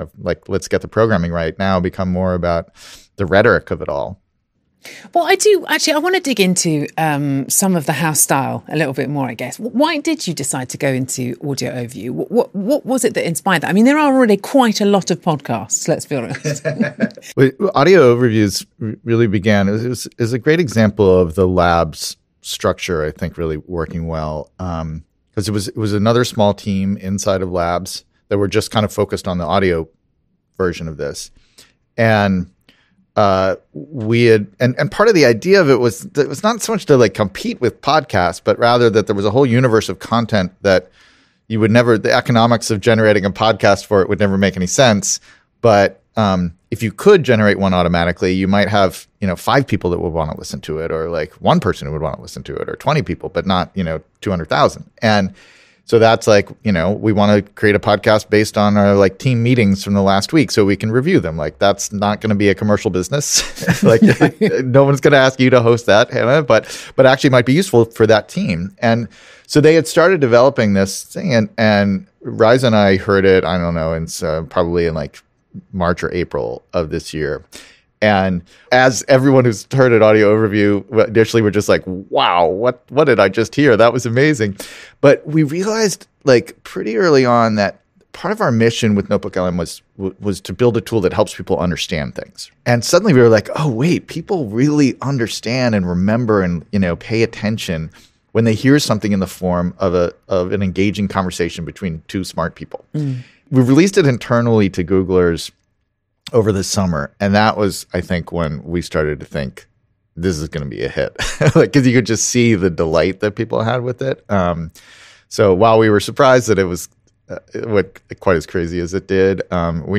BTW - This is a podcast in an interview style (much more dynamic) and much more engaging! read more